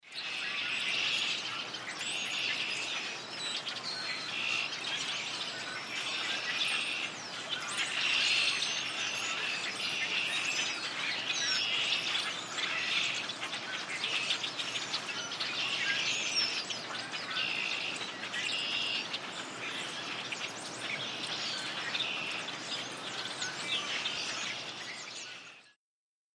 Flock of blackbirds, redwings, grackles etc. ( march, New York state )